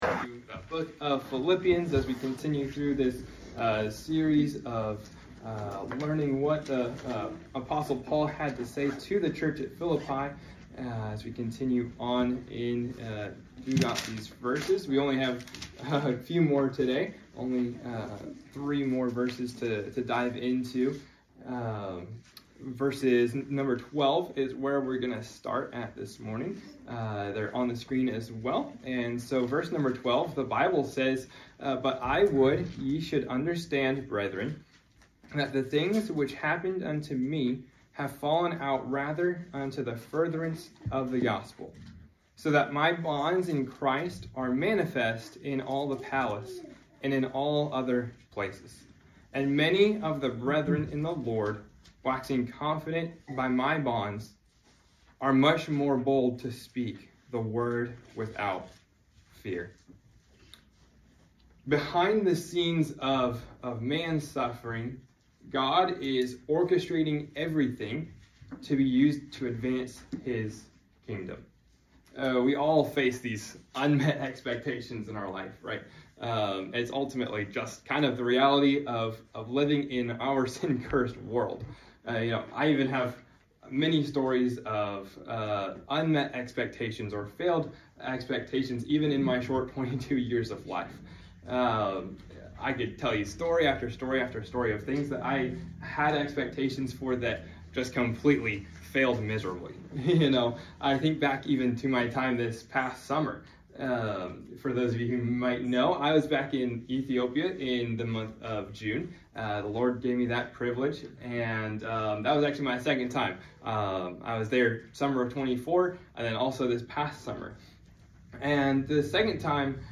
Sermons Archive - Page 2 of 5 - Southwest Baptist Church